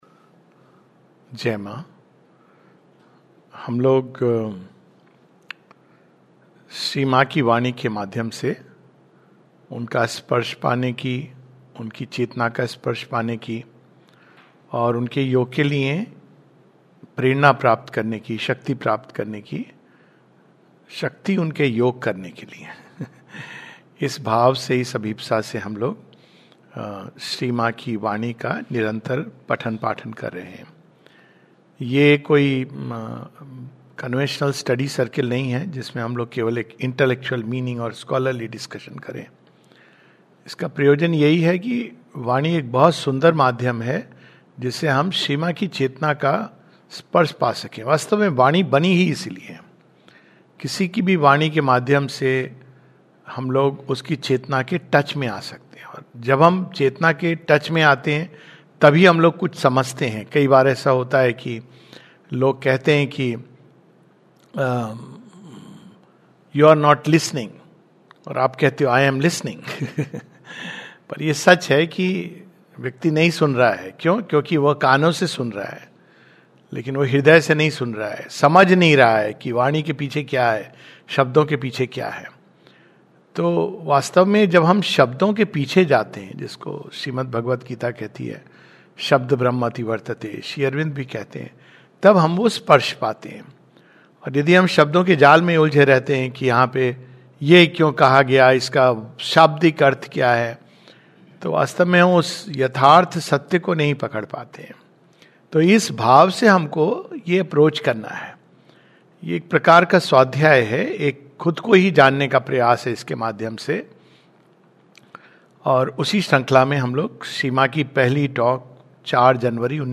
[The Triple Path of Gyana Karma and Bhakti]. This webinar continues from The Mother's conversation on the 04th and 11th January 1956.